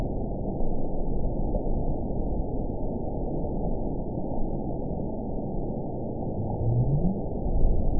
event 920672 date 04/03/24 time 02:13:16 GMT (1 year, 2 months ago) score 9.61 location TSS-AB01 detected by nrw target species NRW annotations +NRW Spectrogram: Frequency (kHz) vs. Time (s) audio not available .wav